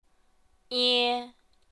舒声韵的示范发音为阴平调，入声韵则为阴入调。
ie~.mp3